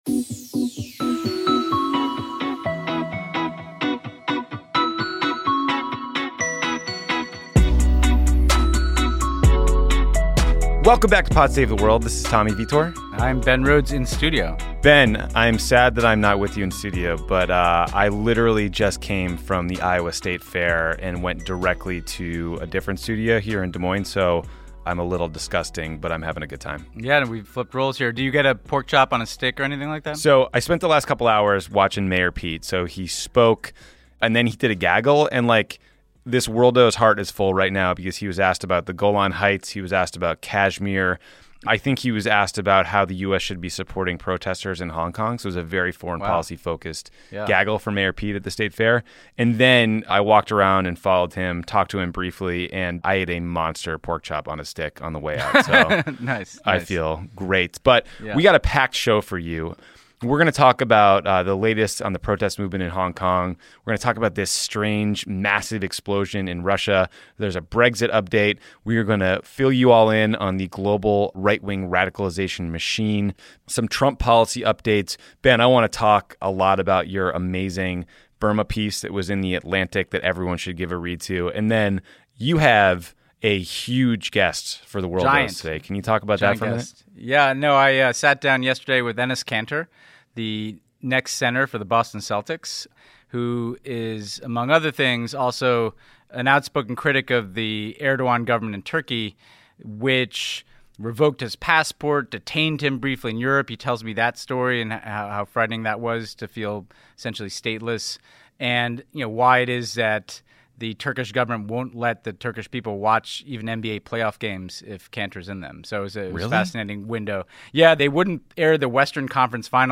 Then Enes Kanter, a professional basketball player for the Boston Celtics, joins Ben in the studio to talk about human rights and the politics of his home country, Turkey - and why President Recep Tayyip Erdogan put out a warrant for his arrest and banned him from coming home.